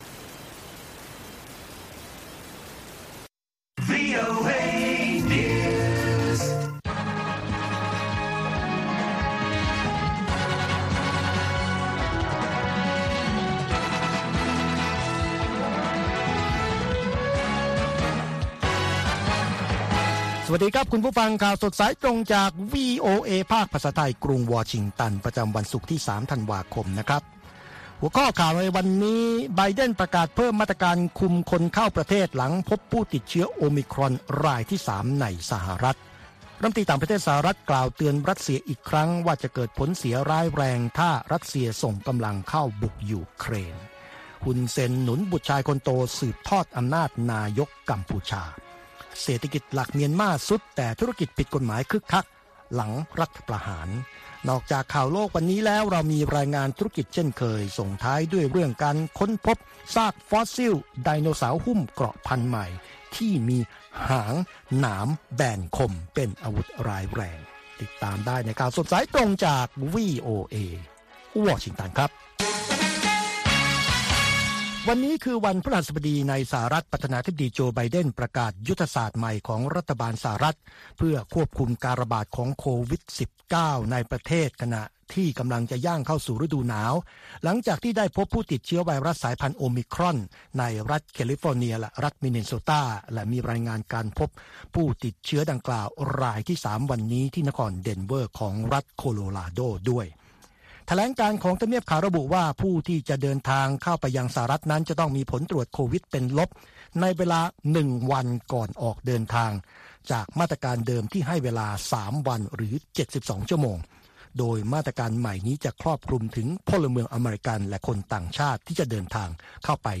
ข่าวสดสายตรงจากวีโอเอ ภาคภาษาไทย ประจำวันศุกร์ที่ 3 ธันวาคม 2564 ตามเวลาประเทศไทย